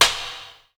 A#3 STICK0JR.wav